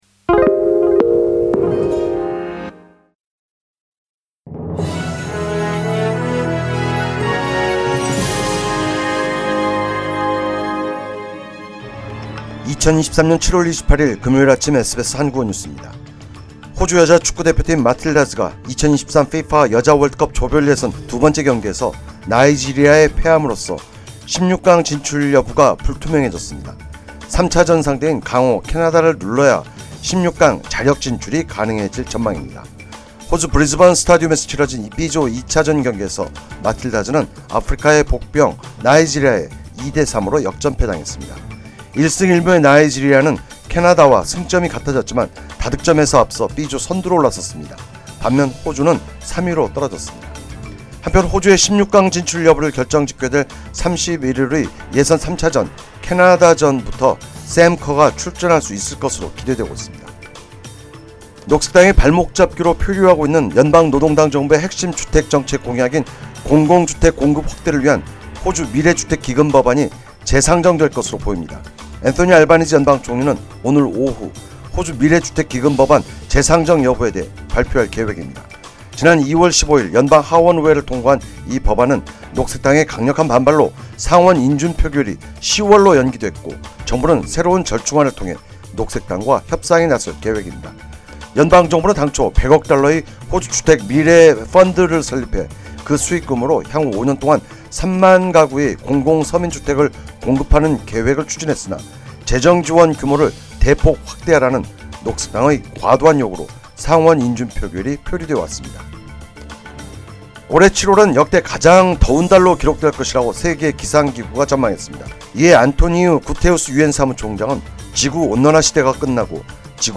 2023년 7월28일 금요일 아침 SBS 한국어 뉴스입니다.